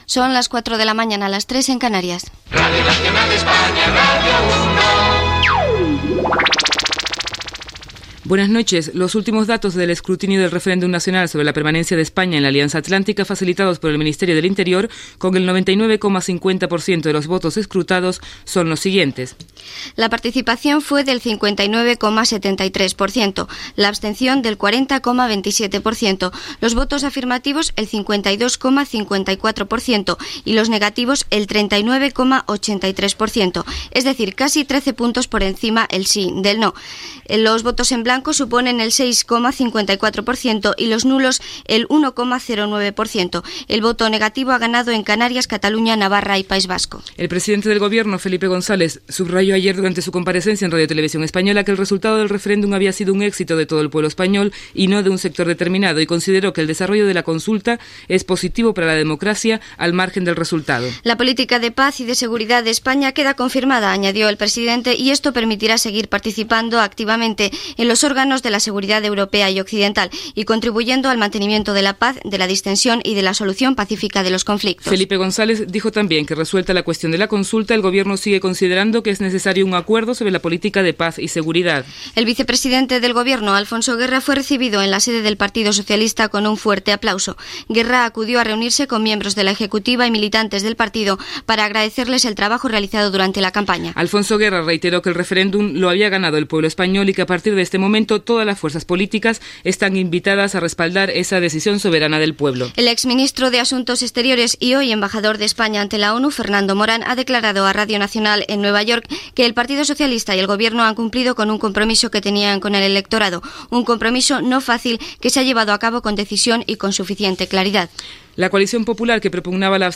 Hora, indicatiu de la ràdio, resultats del referèndum sobre la permanència d'Espanya a l'OTAN, organitzat el dia anterior a Espanya.
Informatiu